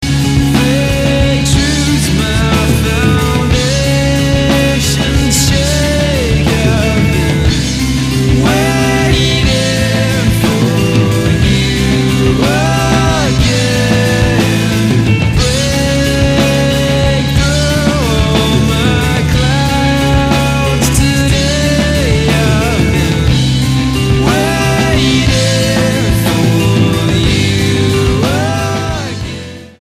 STYLE: Rock
Bristol-based four-piece